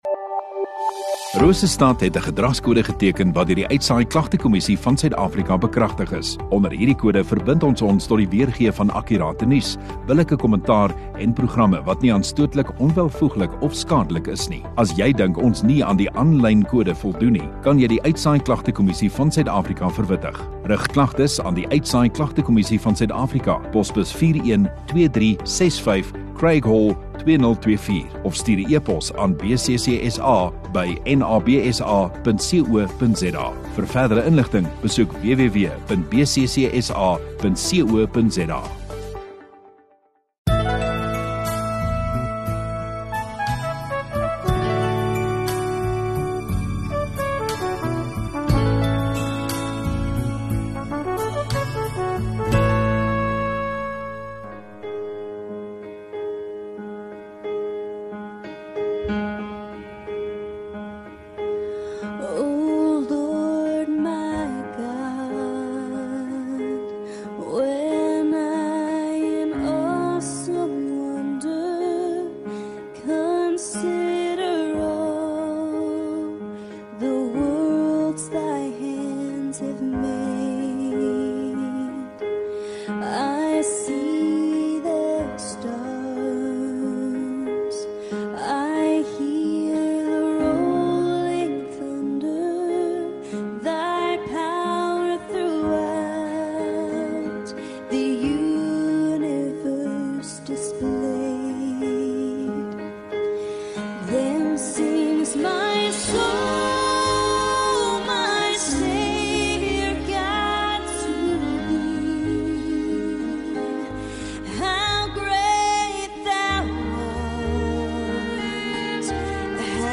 30 Aug Saterdag Oggenddiens